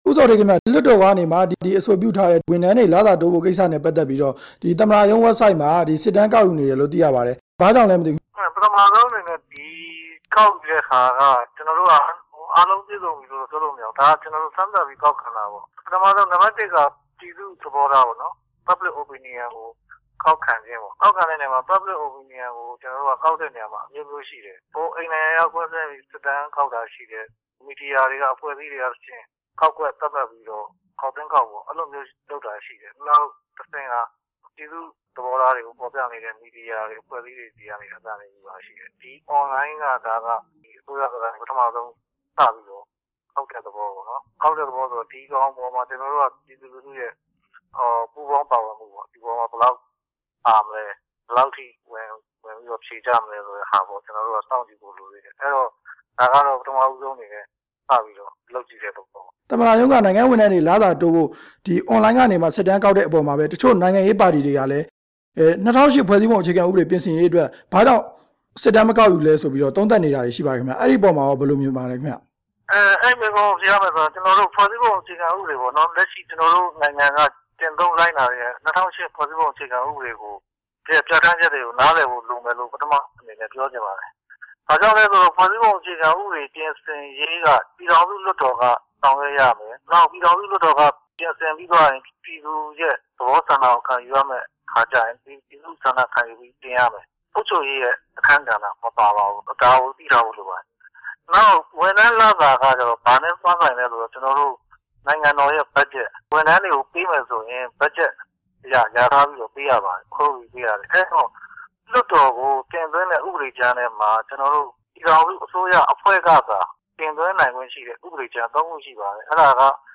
အွန်လိုင်းကနေ မဲပေးတဲ့စနစ်နဲ့ ပြည်သူလူထုသဘောထား ကောက်ခံနေတာနဲ့ပတ်သက်ပြီး မေးမြန်းချက်